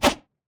punch_short_whoosh_30.wav